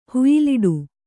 ♪ huyiliḍu